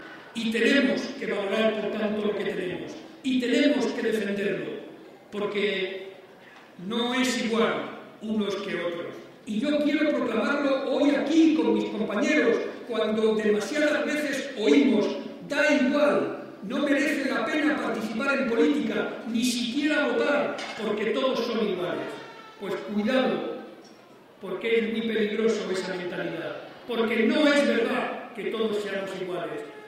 El secretario regional del PSOE y presidente de C-LM, participó en la tradicional comida de Navidad de los socialistas de Albacete.
Cortes de audio de la rueda de prensa